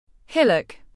Hillock /ˈhɪl.ək/